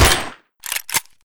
ks23_shoot_silencer1.ogg